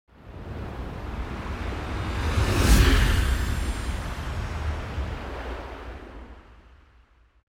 دانلود آهنگ طوفان 8 از افکت صوتی طبیعت و محیط
دانلود صدای طوفان 8 از ساعد نیوز با لینک مستقیم و کیفیت بالا
جلوه های صوتی